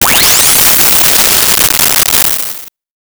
Laser21 1
laser21 1.wav